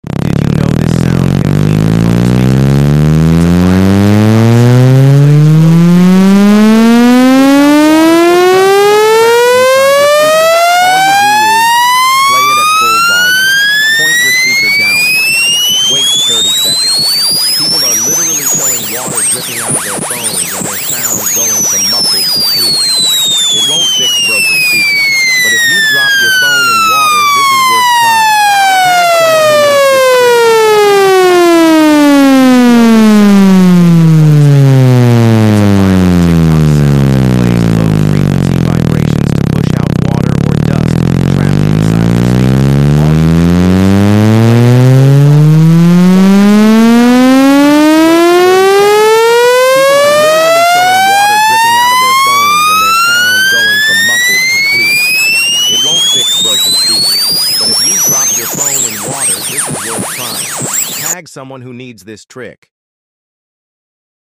Speaker Cleaning Sound water & sound effects free download